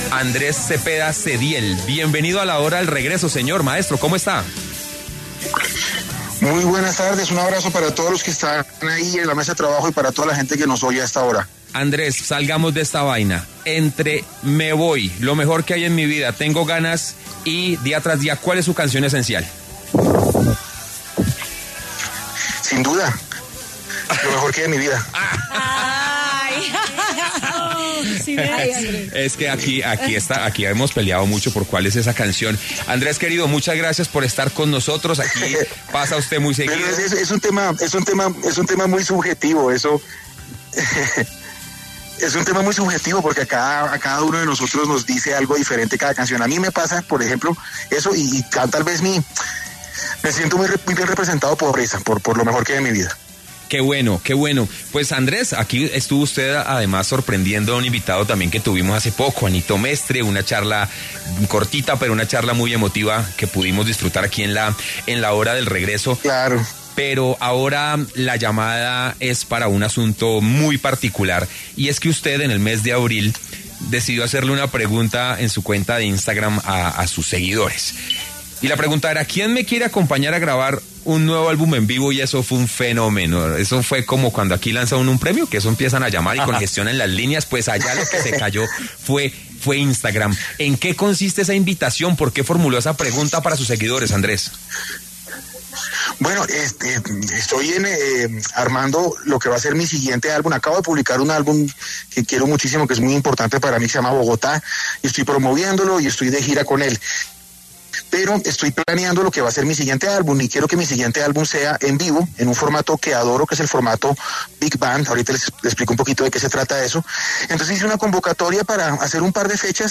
Andrés Cepeda conversó con La Hora del Regreso sobre su serie de conciertos ‘Big Band’, con público en vivo, que se convertirá en su nuevo álbum musical.